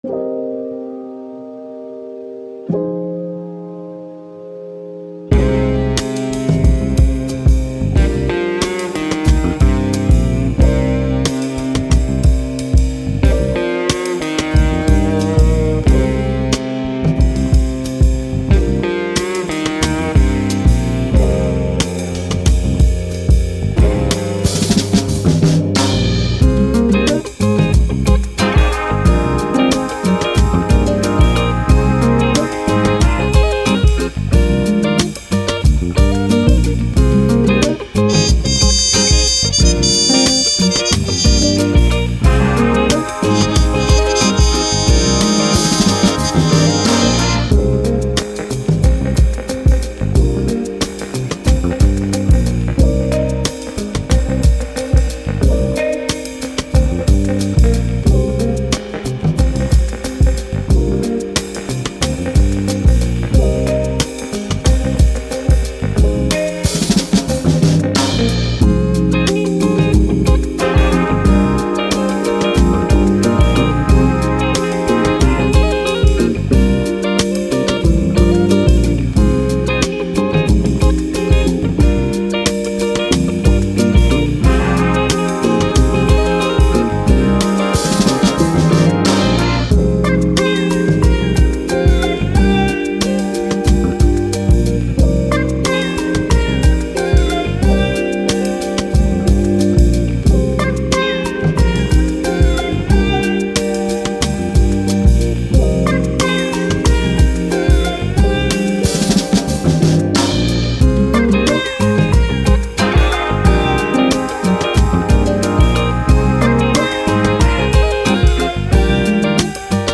Soul, Hip Hop, Vintage, Thoughtful